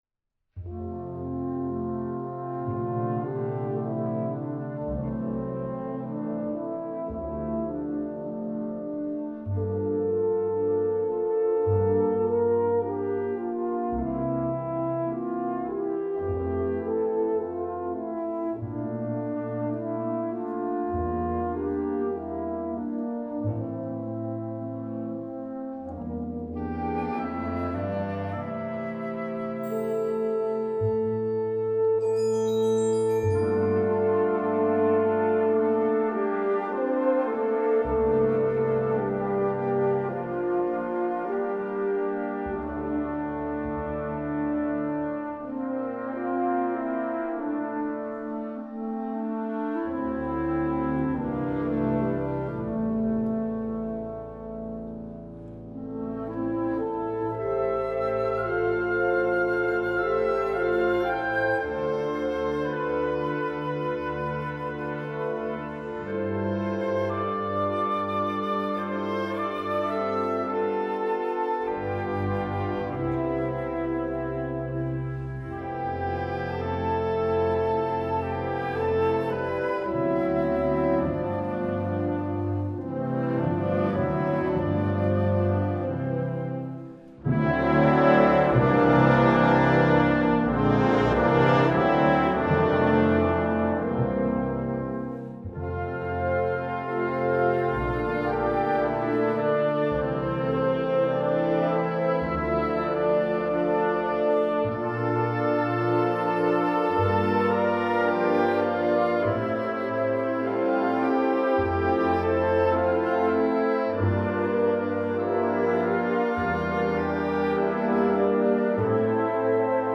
Instrumentation: concert band
classical, french